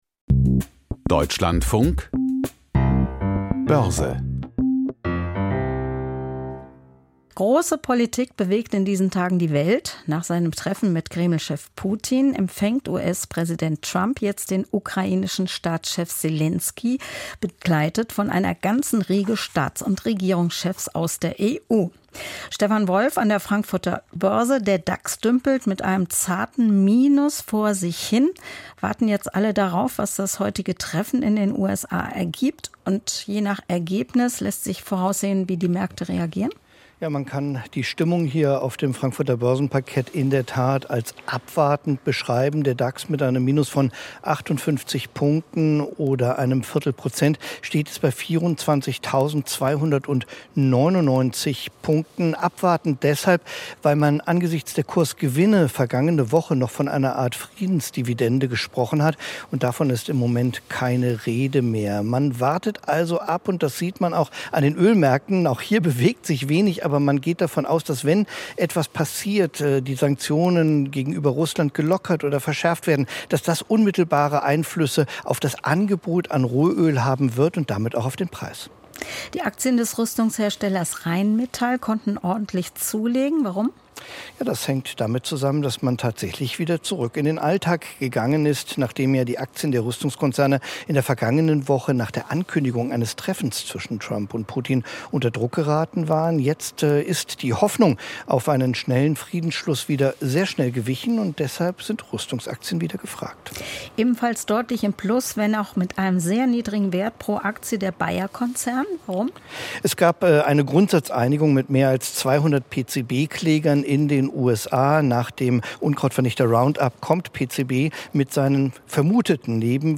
Börsenbericht aus Frankfurt